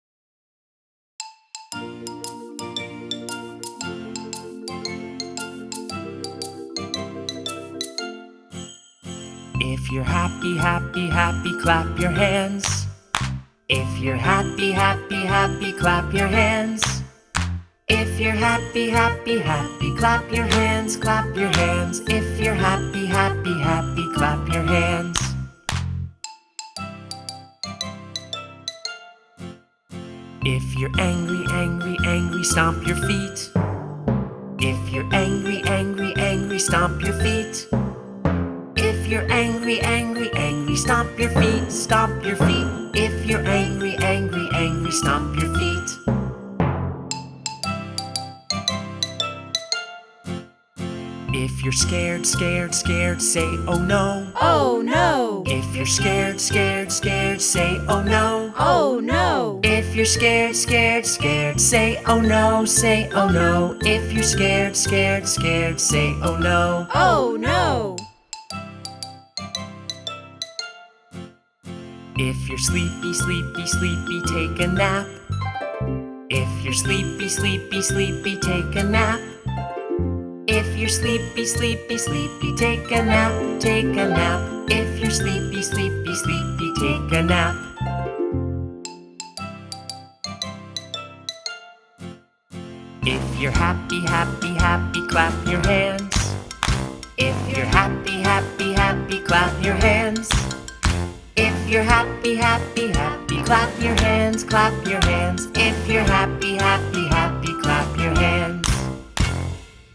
song_happy.wav